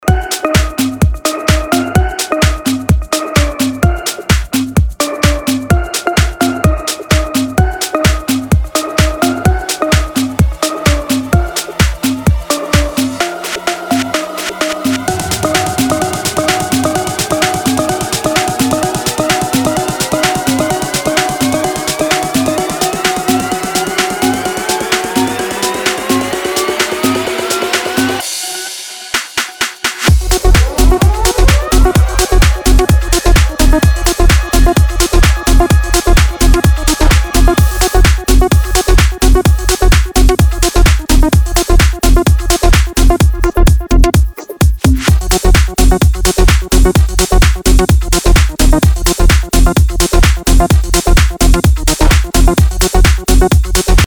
DJ and producer of tech house & house music
His style is unique and electrifying.
DJ